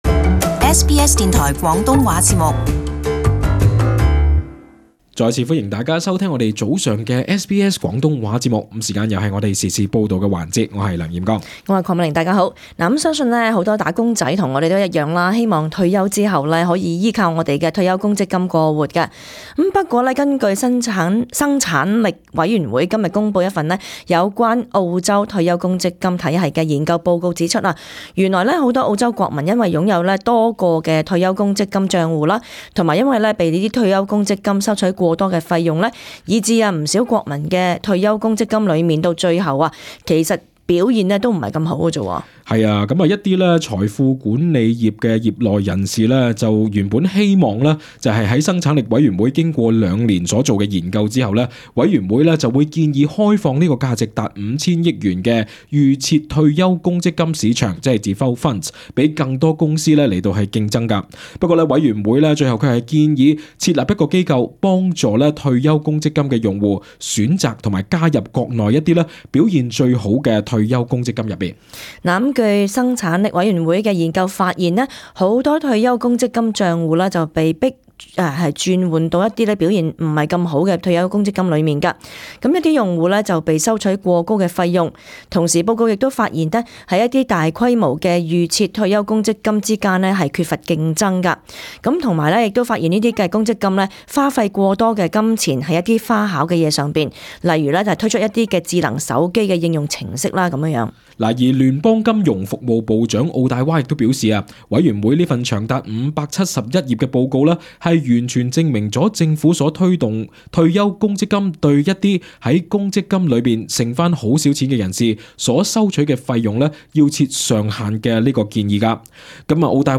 【時事報導】生產力委員會：急需改革公積金制度